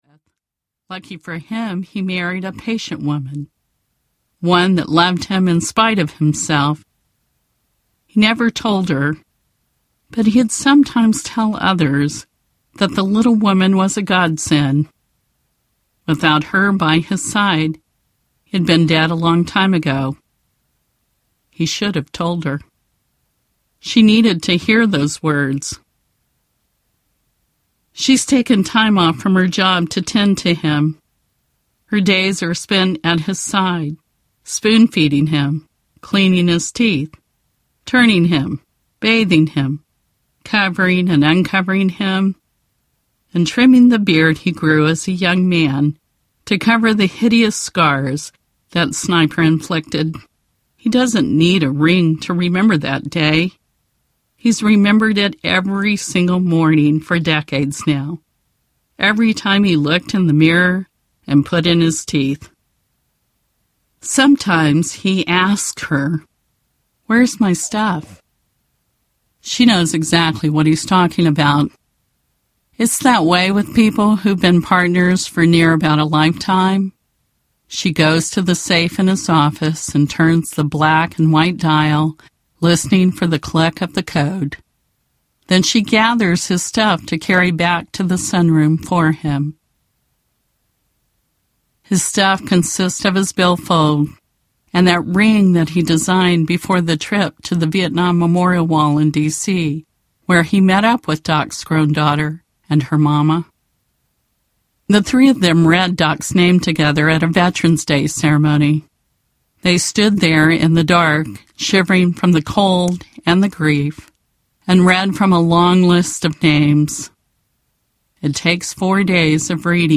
Will Jesus Buy Me a Double-Wide? Audiobook
6.6 Hrs. – Unabridged